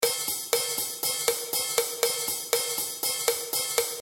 Синкопа
Появляется некая оживленность, грув во второй половине рисунка, где два ритмических акцента приходятся на слабые доли.
Этот эффект дают синкопы.